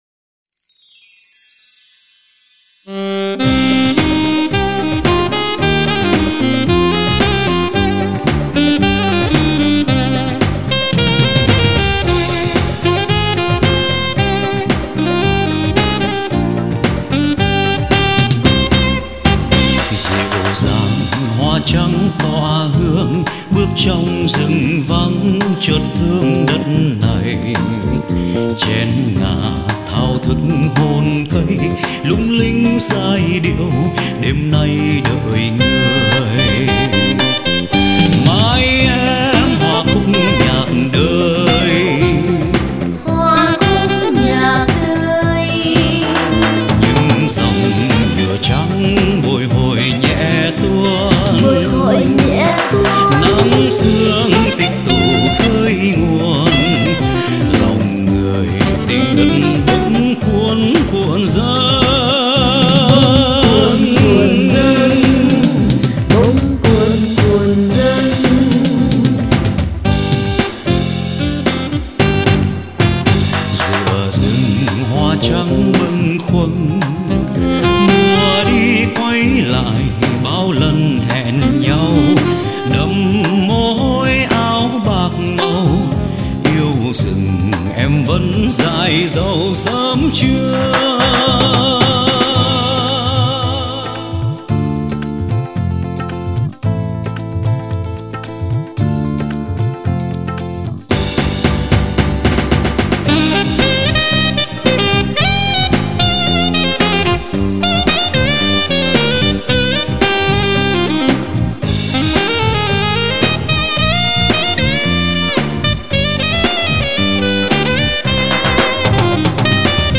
Hát bè